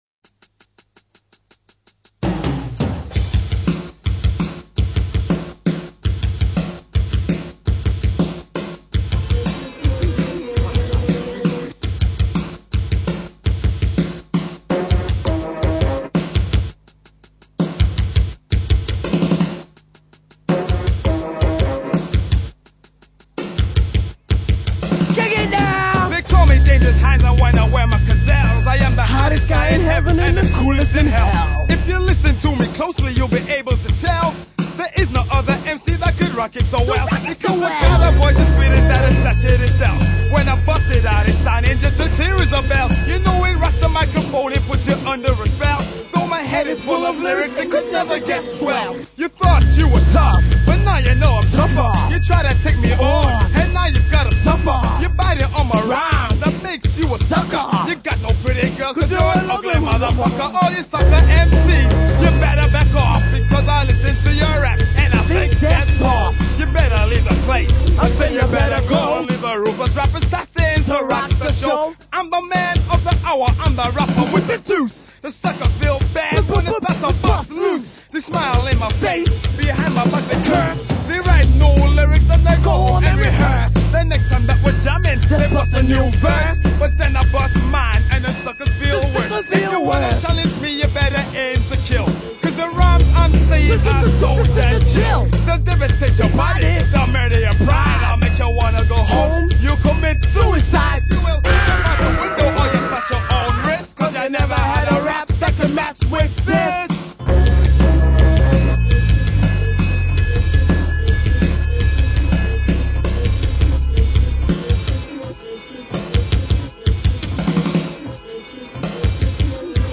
Unreleased Demo